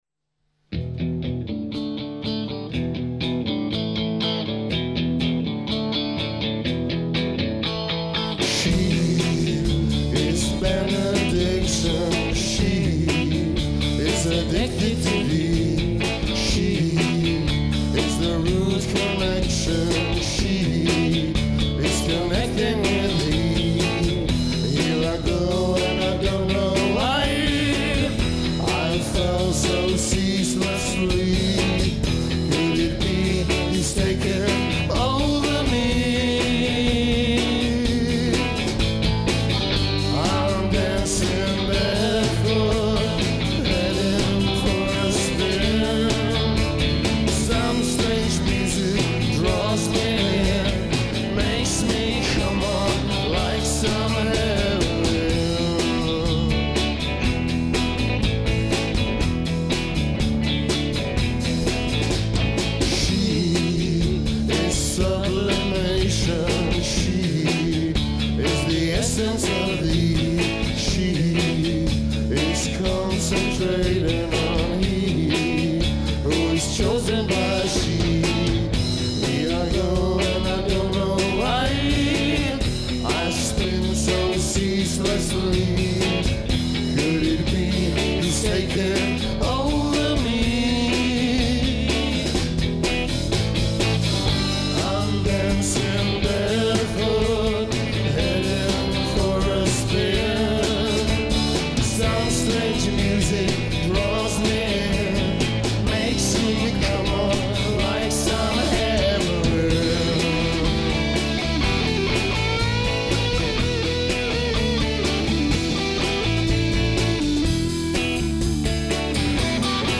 Stará Pekárna 26.8.2011,